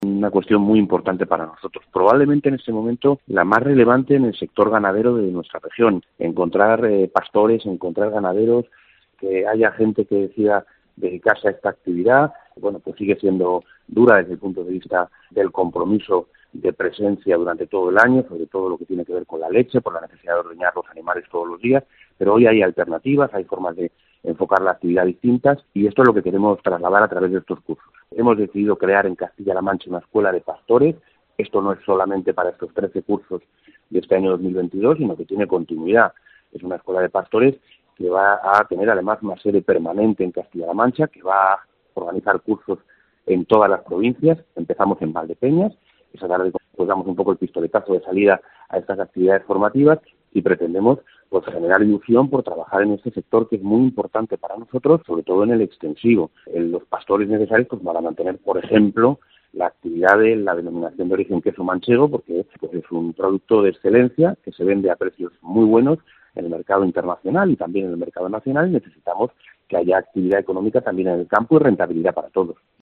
Hoy en Herrera en COPE C-La Mancha, el consejero de Agricultura, Agua y Desarrollo Rural los detalles de la recien estrenada Escuela de Pastores, esta tarde en Valdepeñas.